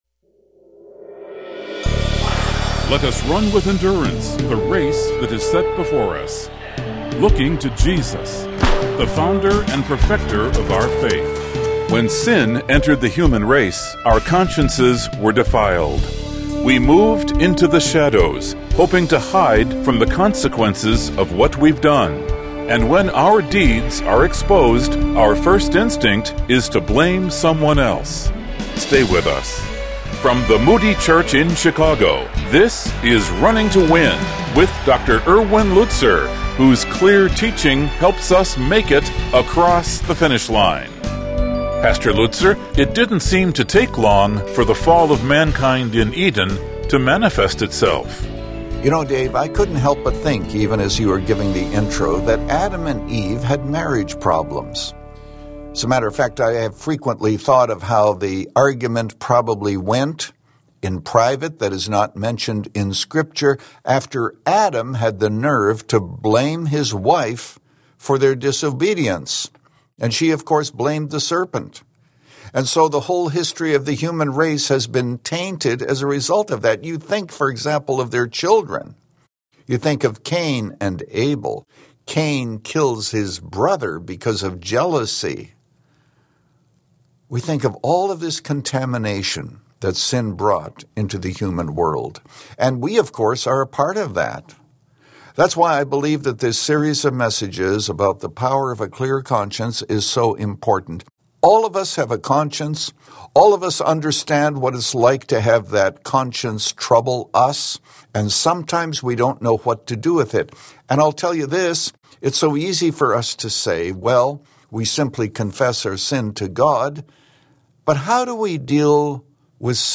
Living In The Shadows "“ Part 2 of 2 Podcast with Erwin Lutzer